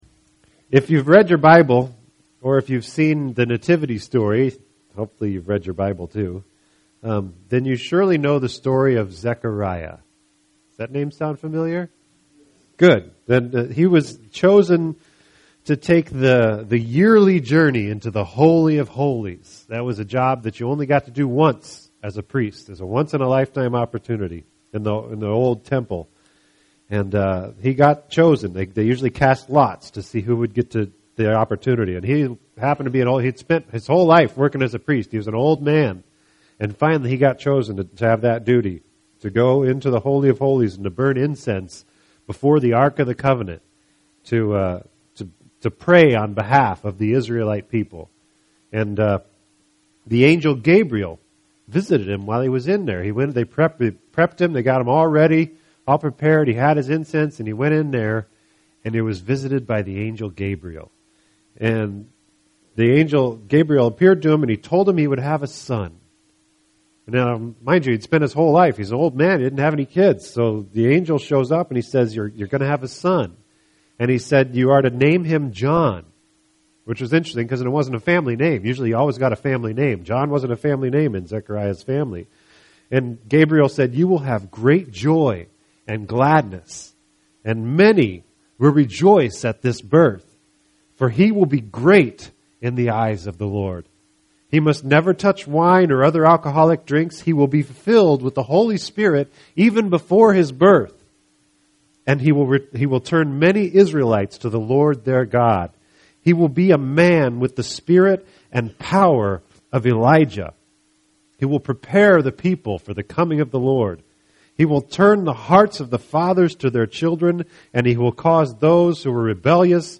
This entry was posted on Friday, October 26th, 2012 at 1:23 am and is filed under Sermons.